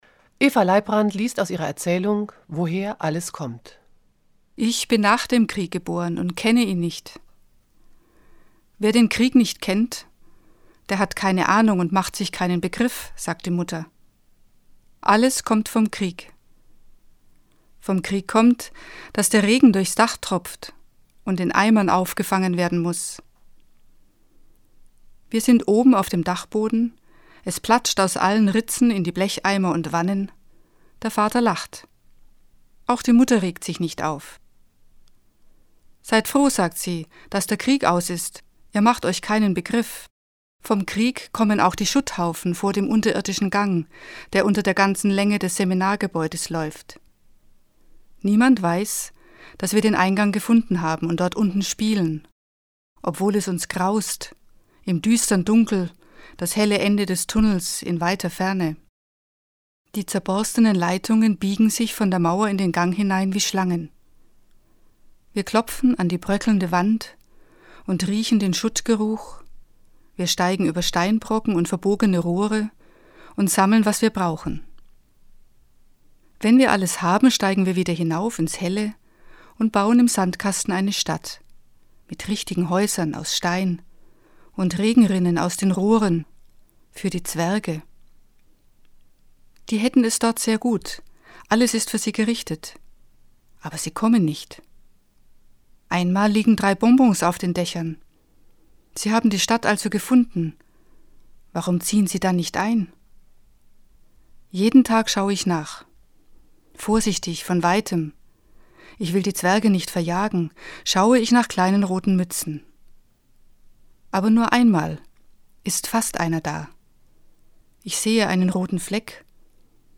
Es umfasst 40 CDs, auf denen insgesamt 573 Lesungen enthalten sind.